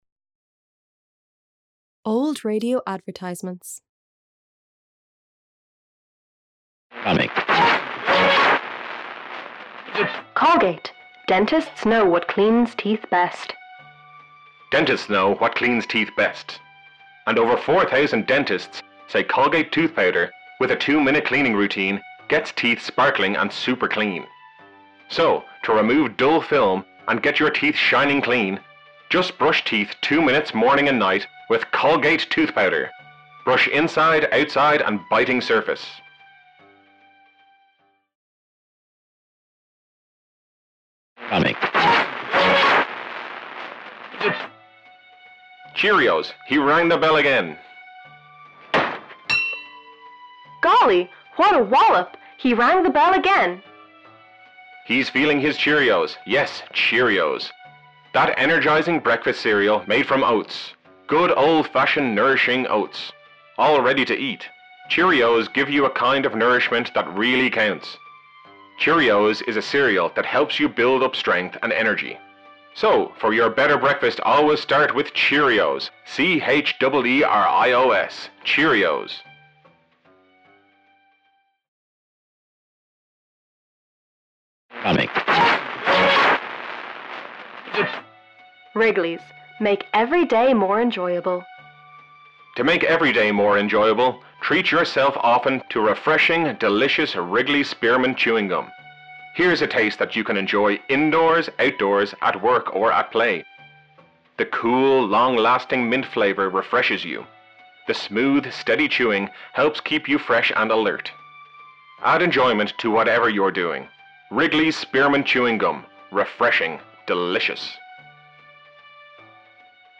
MYE6-U11-Audio-Old_Radio_Advertisements.mp3